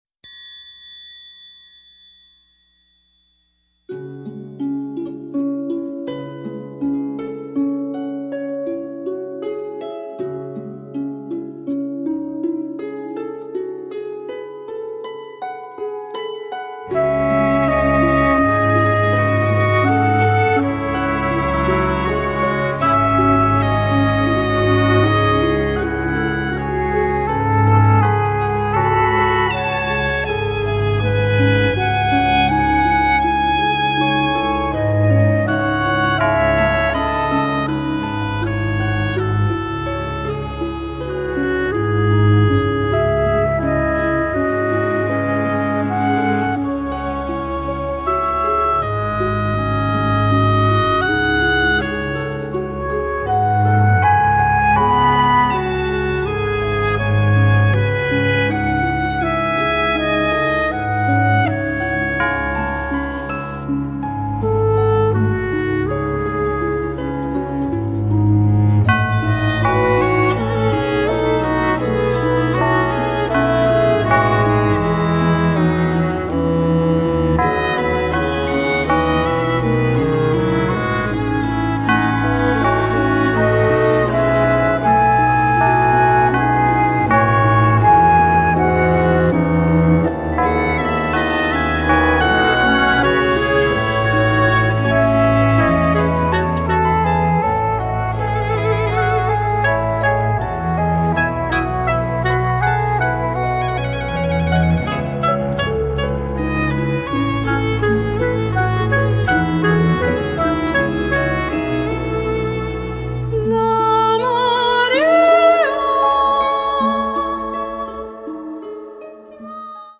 "неоклассика" - от академизма до прогрессивного фолка.